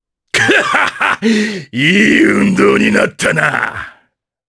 Ricardo-Vox_Victory_jp.wav